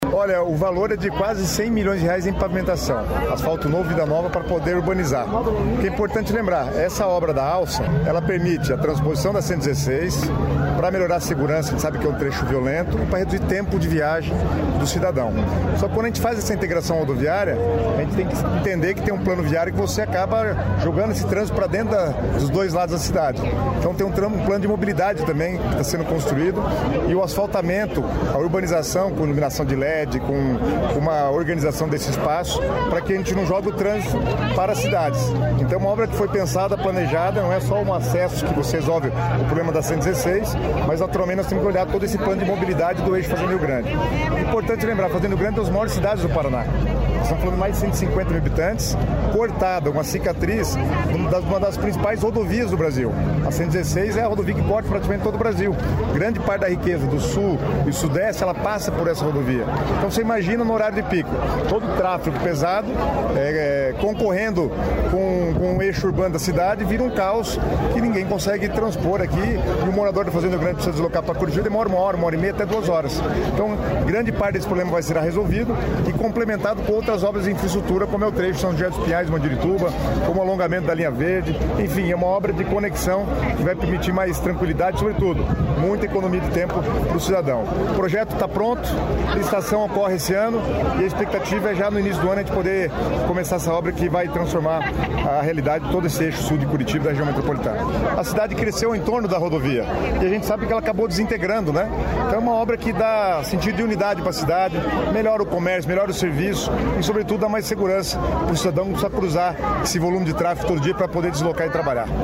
Sonora do secretário das Cidades, Guto Silva, sobre viadutos na BR-116 em Fazenda Rio Grande